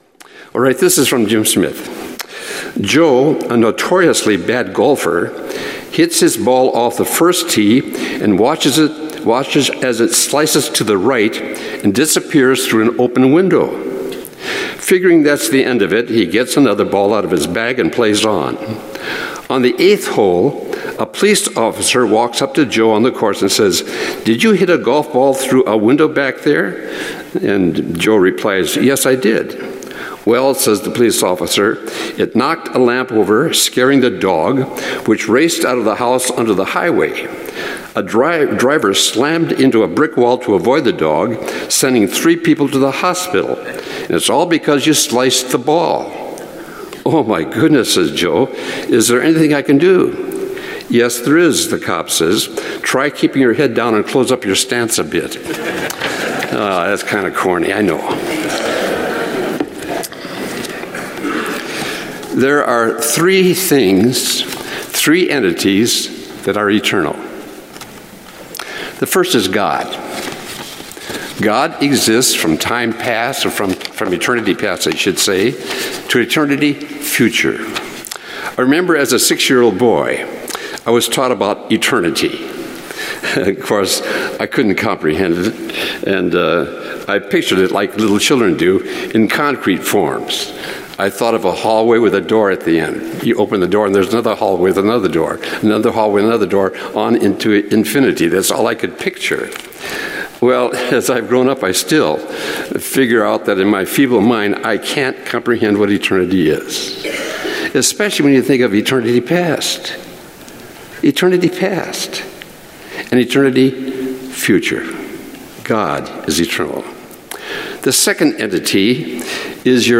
Matthew_Lesson_66.mp3